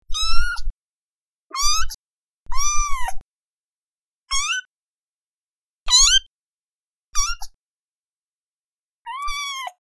جلوه های صوتی
دانلود صدای بچه گربه ملوس و تازه متولد شده از ساعد نیوز با لینک مستقیم و کیفیت بالا